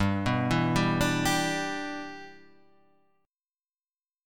G 6th